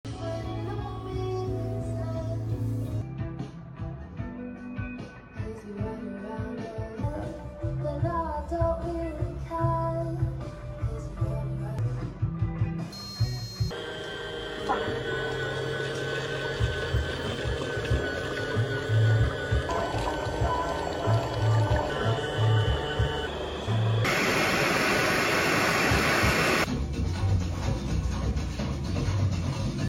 Audio Desk Systeme Vinyl Cleaner sound effects free download